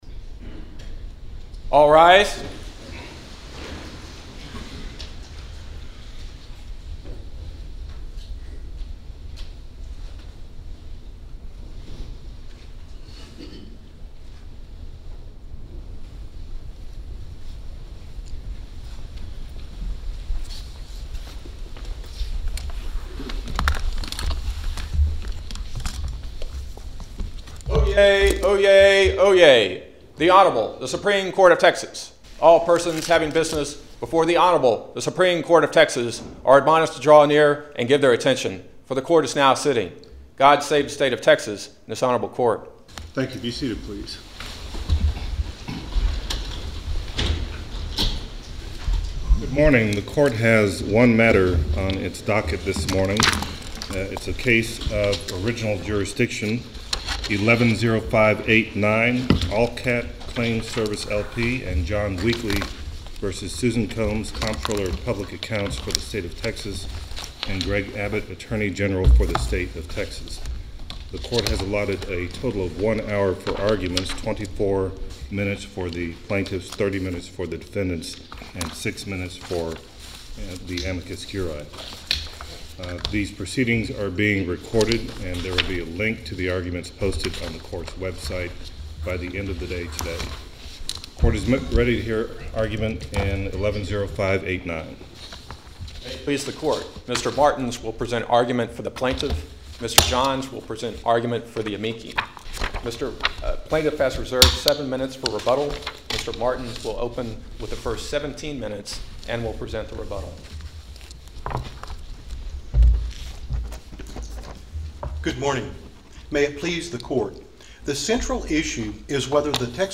Case No. 09-0999 Oral Arguments Audio (MP3)